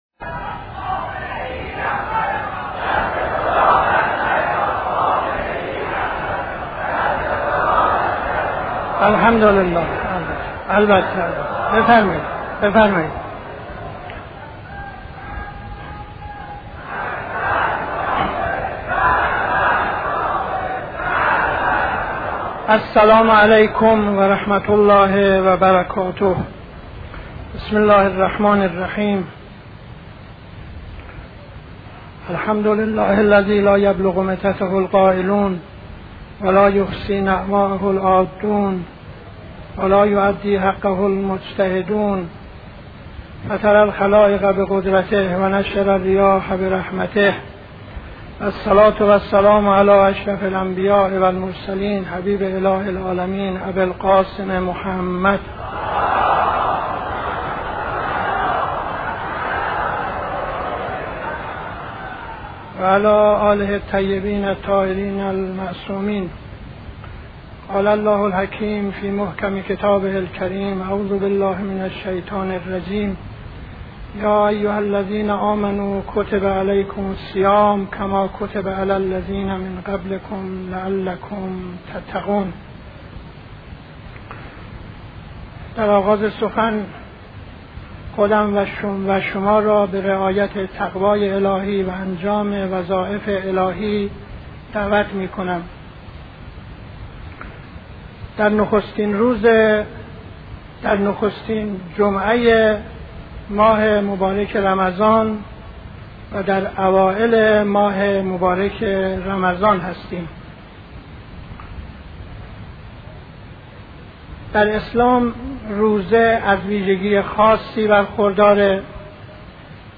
خطبه اول نماز جمعه 29-11-72